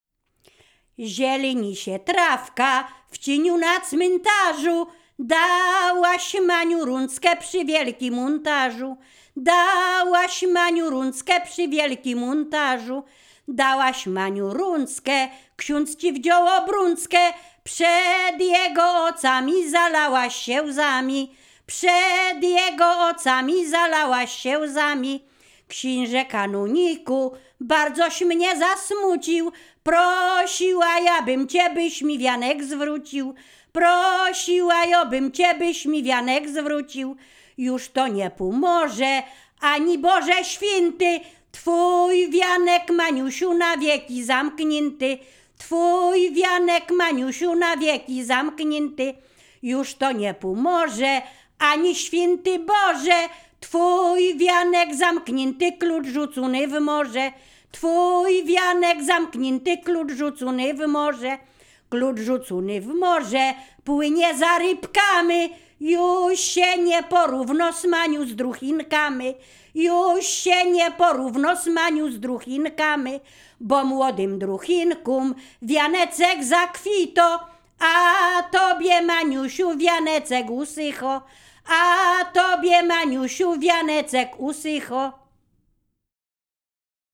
Ziemia Radomska
wesele liryczne miłosne weselne wianek